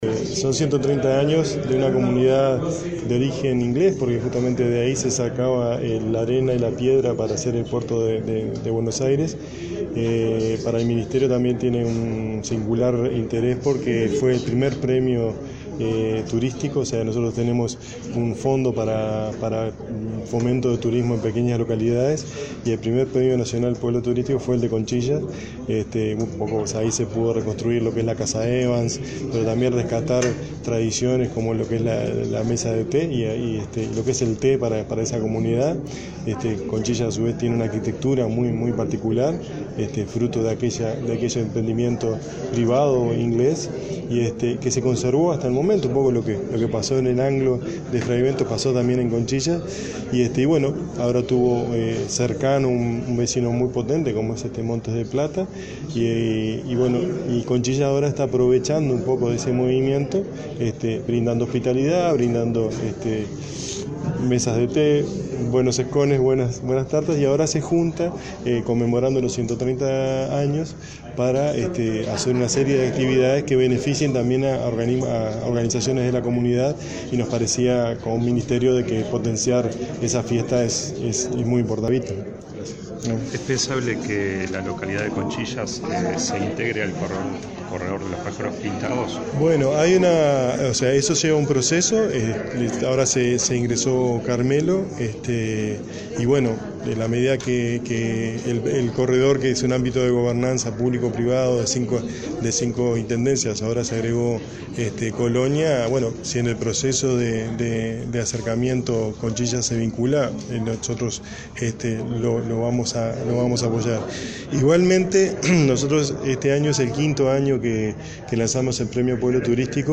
El director nacional de Turismo, Carlos Fagetti, participó de los festejos por los 130 años de Conchillas, en Colonia, oportunidad en la que destacó la infraestructura de la localidad y no descartó que se integre al Corredor Turístico de los Pájaros Pintados.